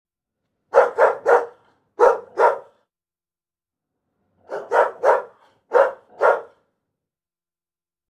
Single Dog Deep Bark Sound Effect Download: Instant Soundboard Button
Dog Sounds69 views